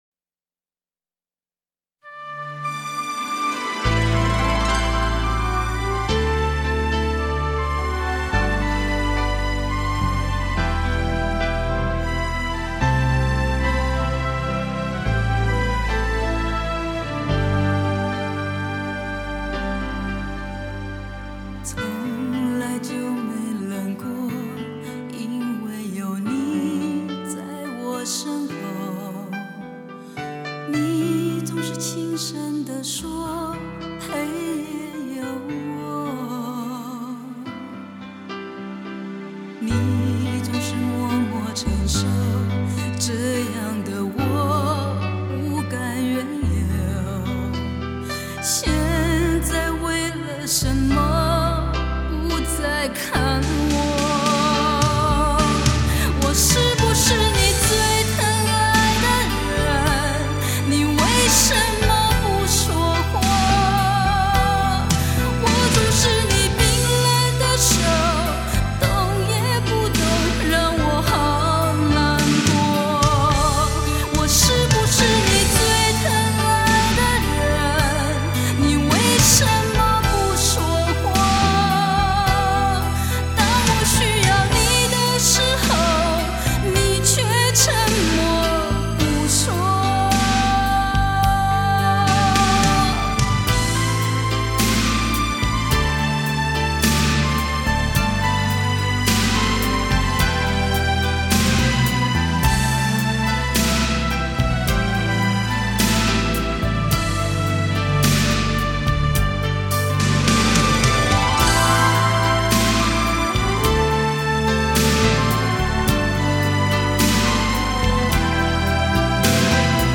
享誉业界的HIFI音效天碟 务求征服你最挑剔的耳朵
游刃有余的歌声，精雕细琢的超强录音效果，足以销魂蚀骨令人中毒